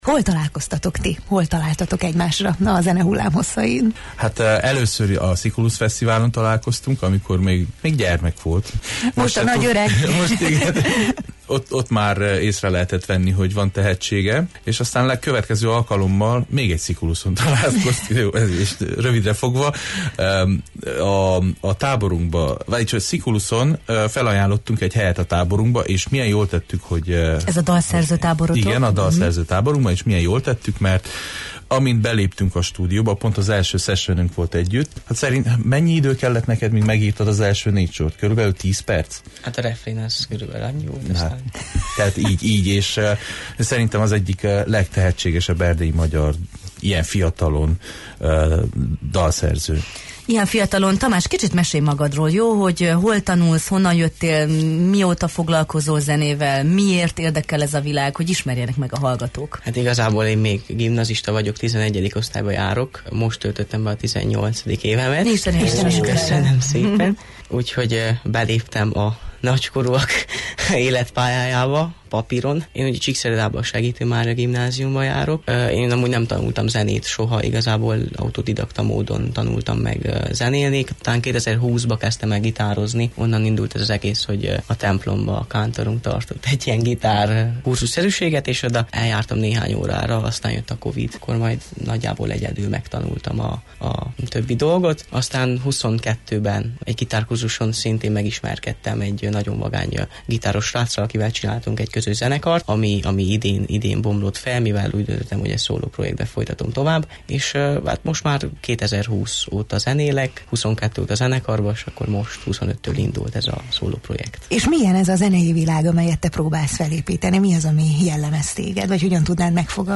Az interjúban szó esett arról is, milyen meghatározó szerepe van a támogatásnak egy pályakezdő zenész életében.